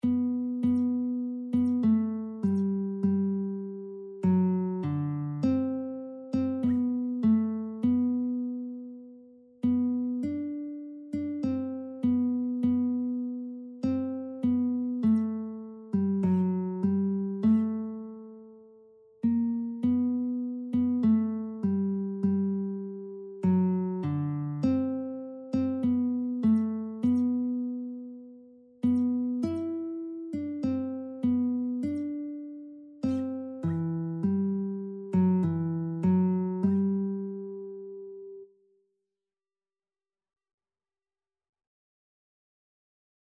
4/4 (View more 4/4 Music)
Classical (View more Classical Lead Sheets Music)